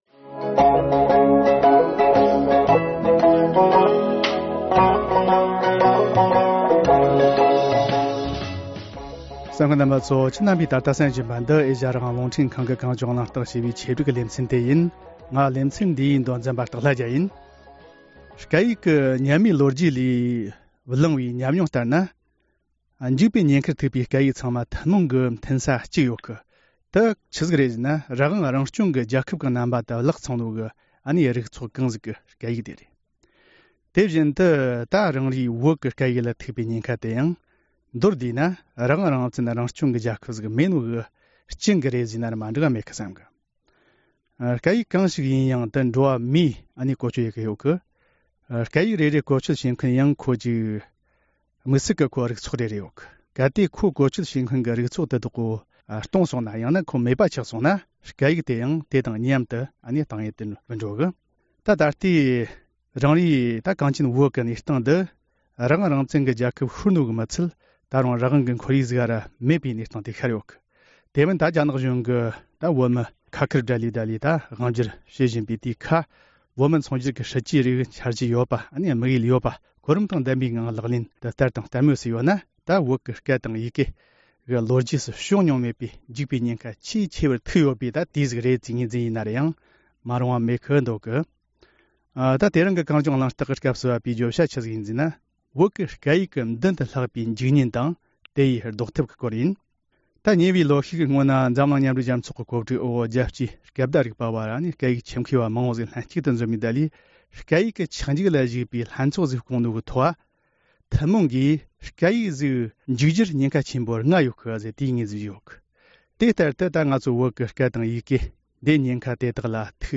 བོད་ཀྱི་སྐད་ཡིག་གི་མདུན་དུ་ལྷགས་པའི་འཇིག་ཉེན་དང་དེ་བཟློག་ཐབས་སྐོར་འབྲེལ་ཡོད་ཁག་ཅིག་དང་གླེང་མོལ་ཞུས་པ།